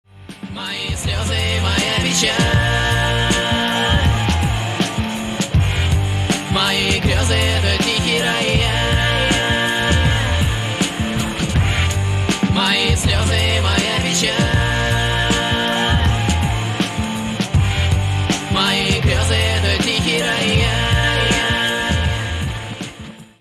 • Качество: 320, Stereo
мужской вокал
лирика
грустные
мелодичные